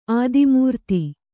♪ ādimūrti